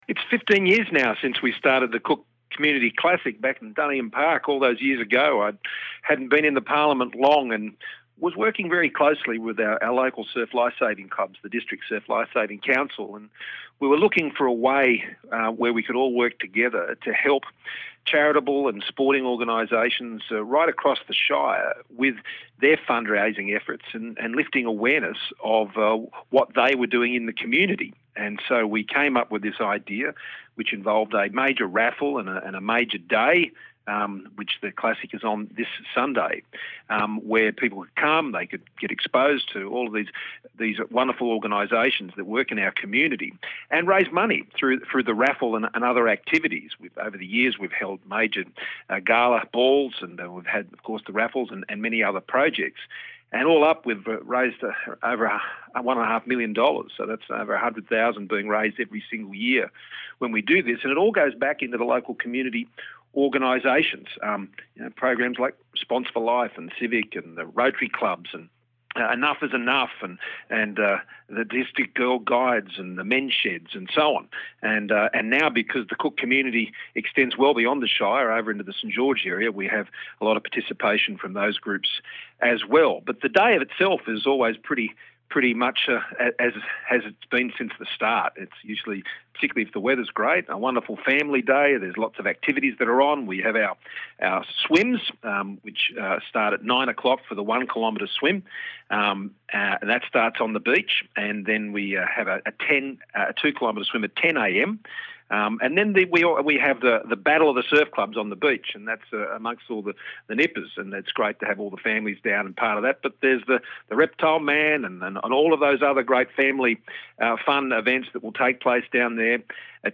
BONUS EPISODE | Federal Member for Cook MP Scott Morrison Interview